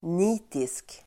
Ladda ner uttalet
nitisk adjektiv, zealous Uttal: [n'i:tisk] Böjningar: nitiskt, nitiska Synonymer: ambitiös, enträgen, idog, ihärdig, plikttrogen, trägen Definition: som visar (stor) nit, plikttrogen (fervent, assiduous, ardent)